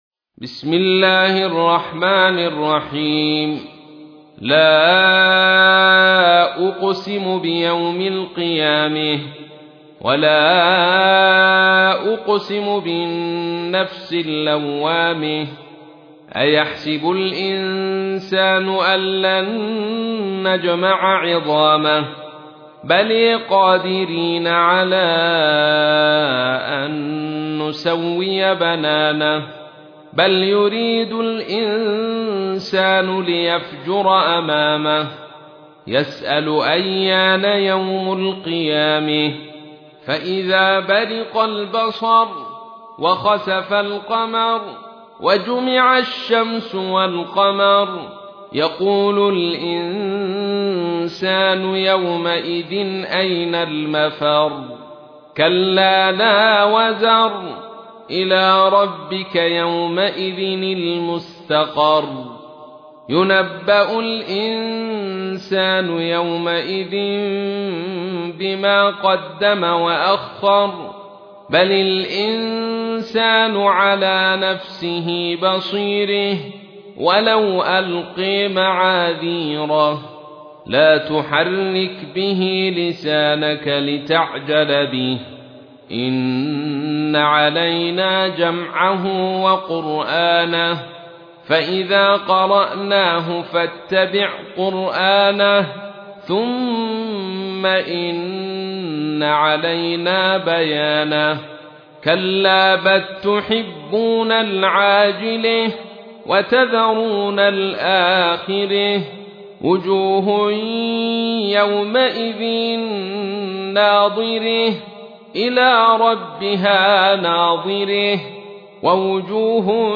تحميل : 75. سورة القيامة / القارئ عبد الرشيد صوفي / القرآن الكريم / موقع يا حسين